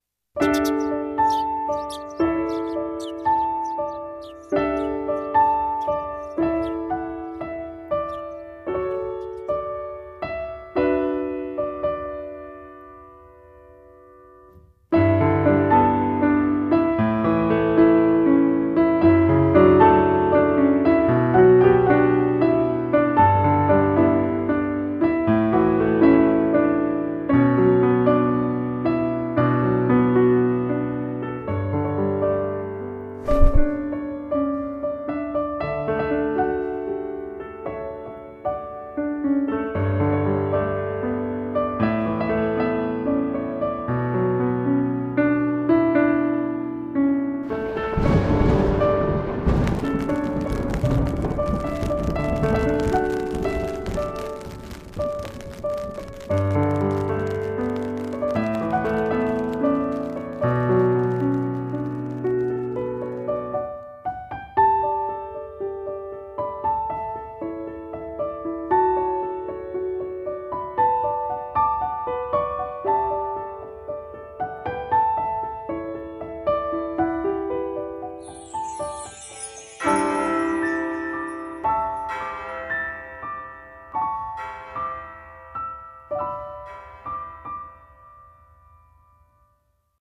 CM風声劇「シルヴィ」